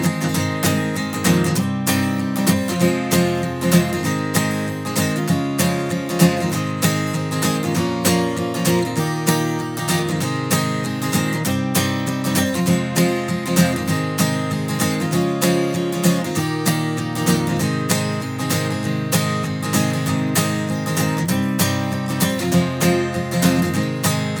navy_acousticguitar_bypassed.flac